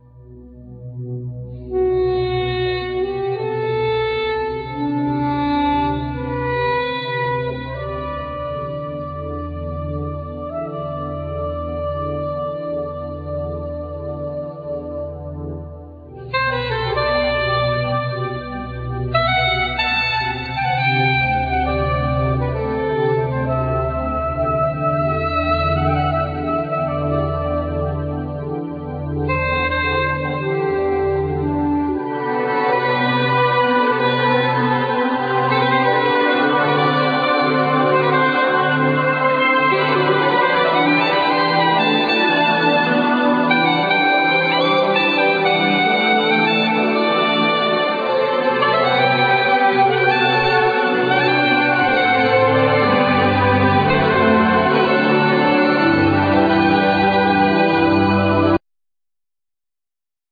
Violin
Viola
Cello
Trumpet
Sax
Clarinet
Piano
Flute
Guitar
Computers,Synthsizers